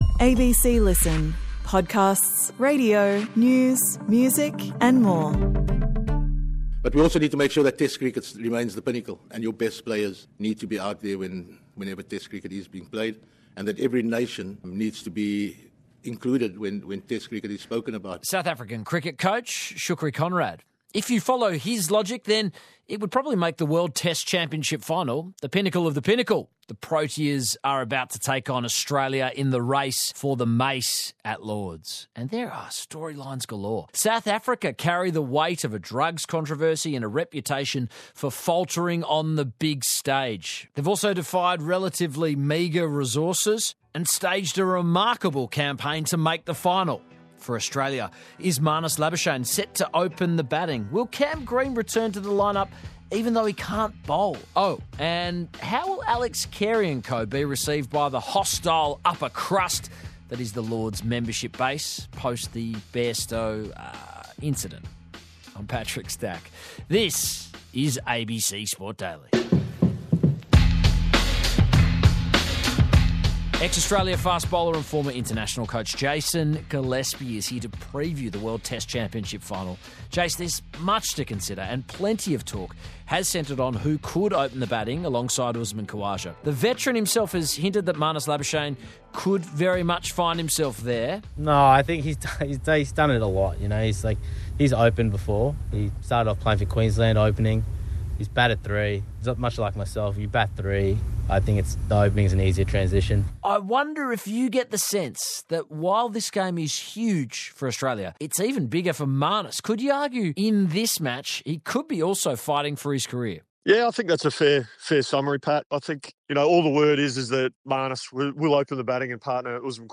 Featured: Jason Gillespie, ex-Test cricketer.